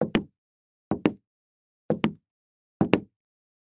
Шепот балетных туфель Щелкунчика